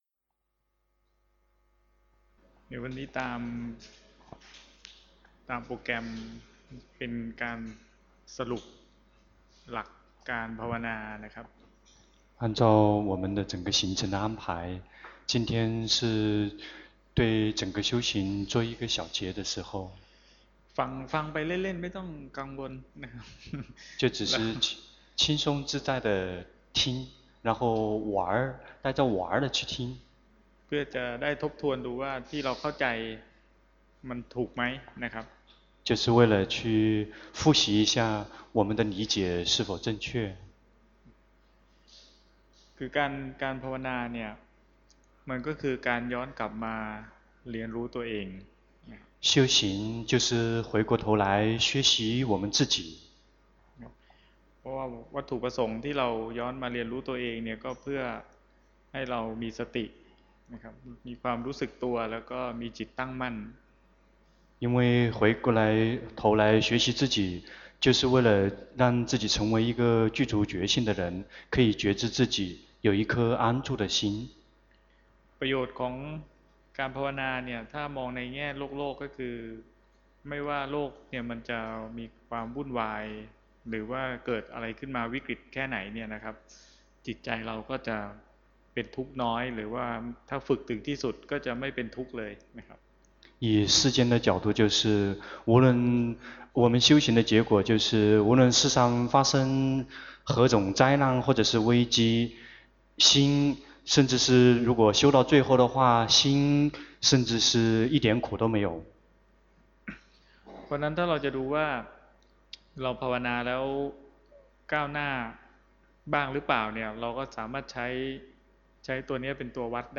長篇法談｜歸零